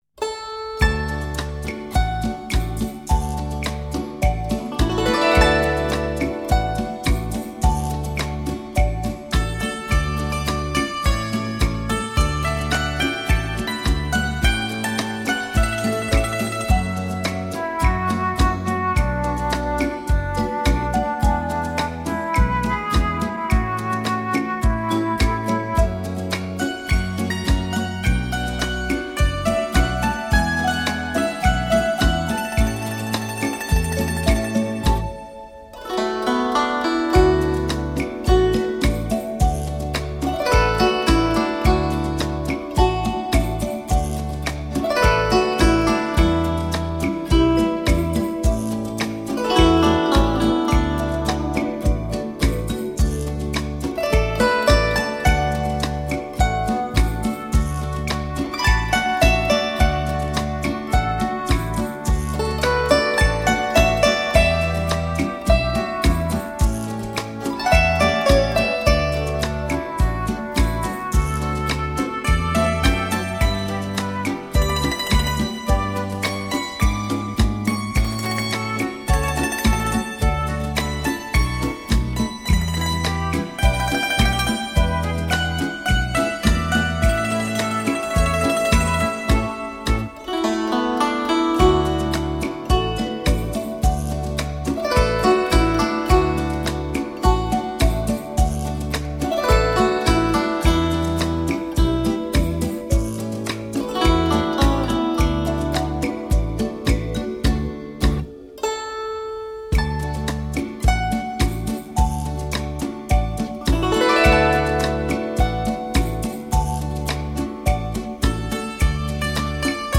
轻柔乐音伴着淡淡筝乐，
牵引出无限写意的夏威夷情调...